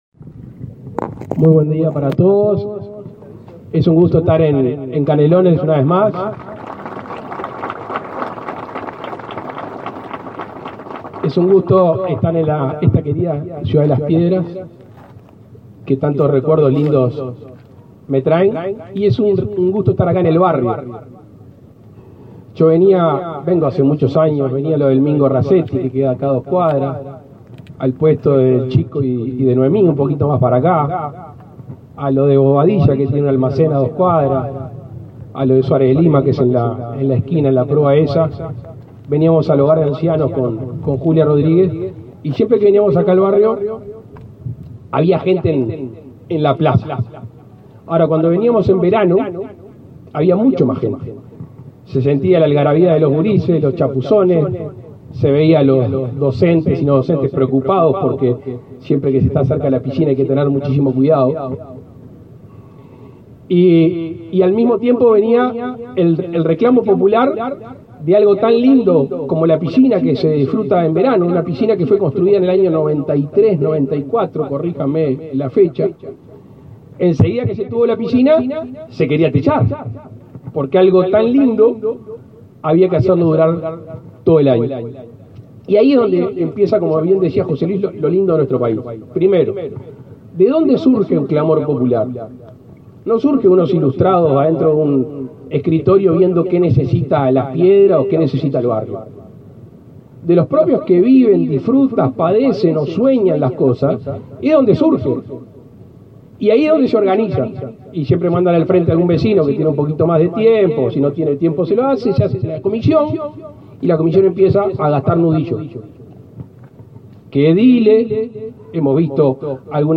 Palabras del presidente de la República, Luis Lacalle Pou
Con la presencia del presidente de la República, Luis Lacalle Pou, el Ministerio de Transporte y Obras Públicas firmó un convenio con la Secretaría Nacional del Deporte, la Intendencia de Canelones, el Municipio de Las Piedras, el Club de Leones de esa localidad y la Asociación Civil Pro Plaza de Deportes 18 de Mayo, para la concreción de obras de cerramiento y climatización de la piscina ubicada en el referido espacio.